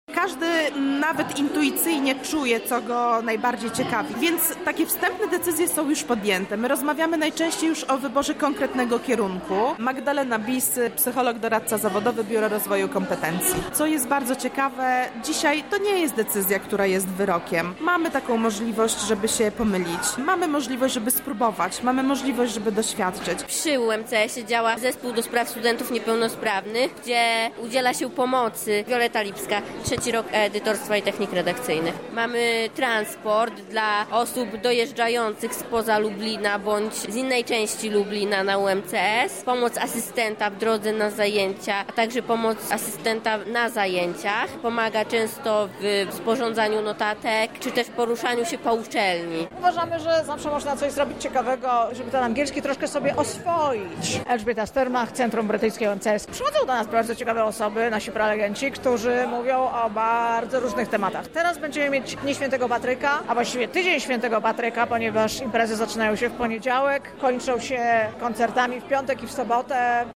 Maturzystom podczas drzwi otwartych w wyborze dalszej drogi życia pomagali doradcy zawodowi, nauczyciele akademiccy oraz sami studenci: